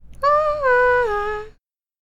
punch02.m4a